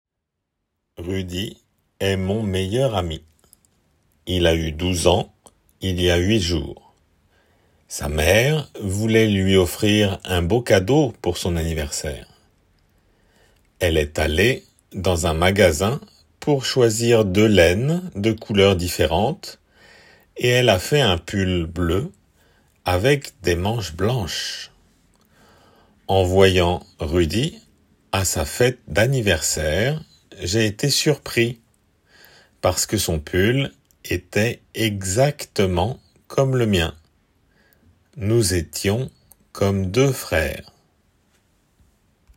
11月に向けて準備 8 書き取りー音声
自然の速さ